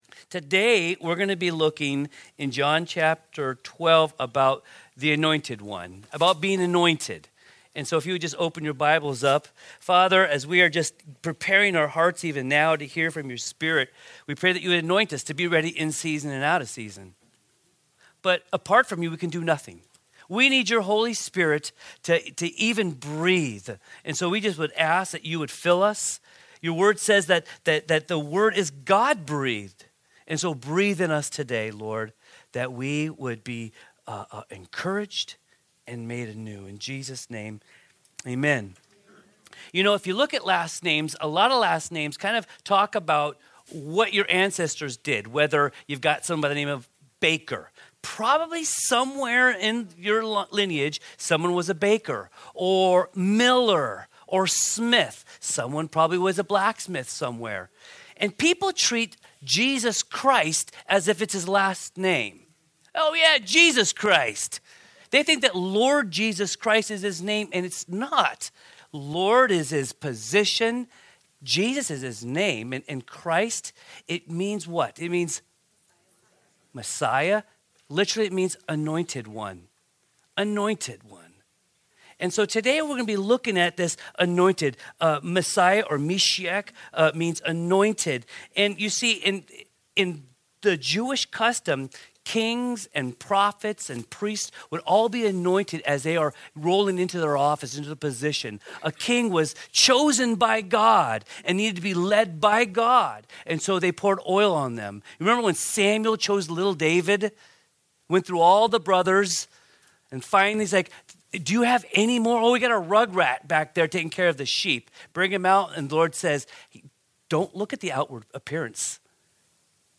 Sermons | Calvary Chapel Lighthouse Fellowship